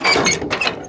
gearup.ogg